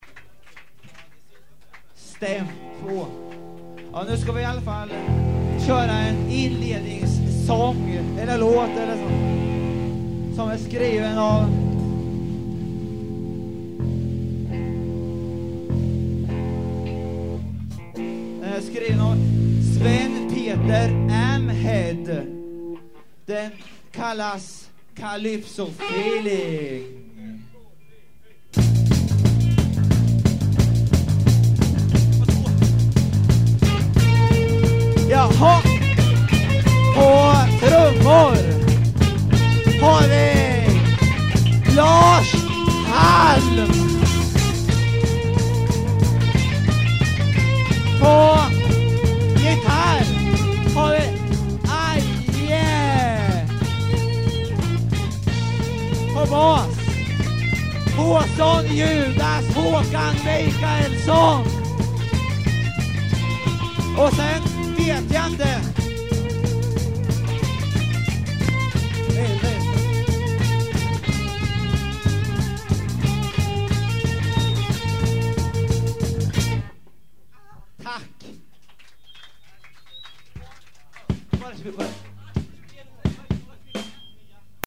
Guitar
Bass
Drums
Voice
Live at Kannan 1980